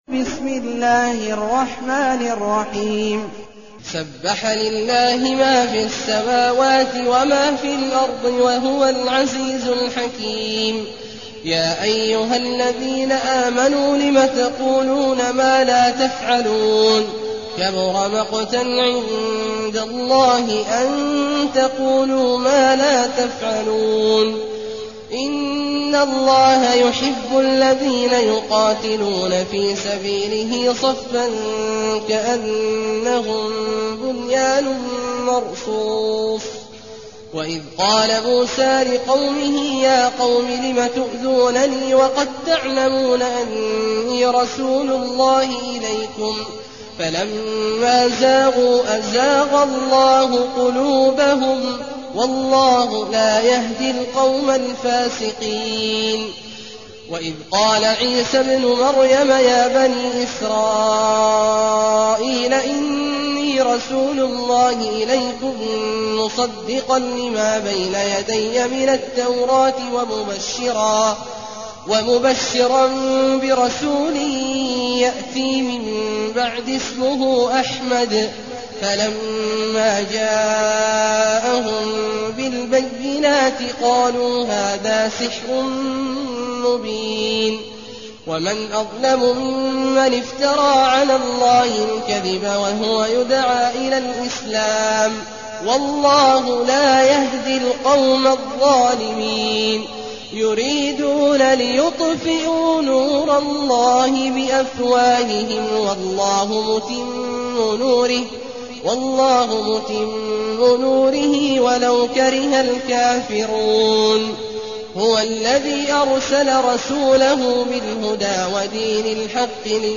المكان: المسجد النبوي الشيخ: فضيلة الشيخ عبدالله الجهني فضيلة الشيخ عبدالله الجهني الصف